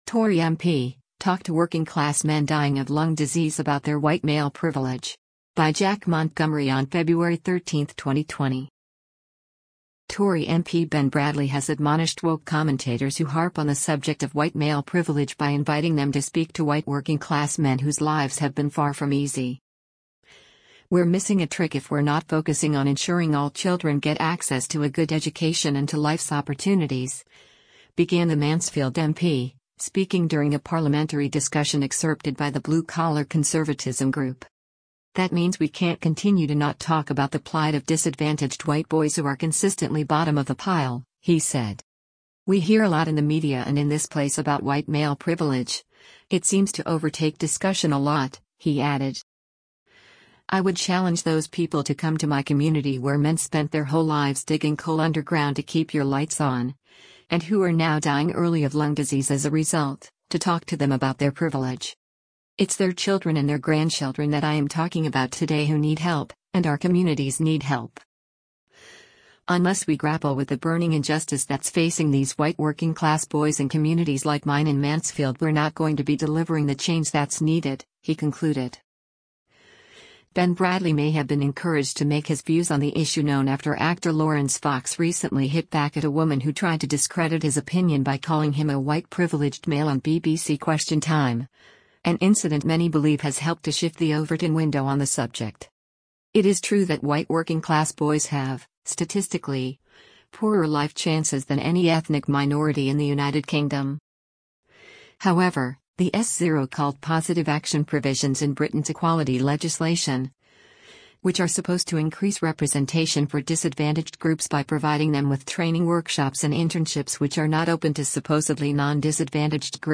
“We’re missing a trick if we’re not focusing on ensuring all children get access to a good education and to life’s opportunities,” began the Mansfield MP, speaking during a parliamentary discussion excerpted by the Blue Collar Conservatism group.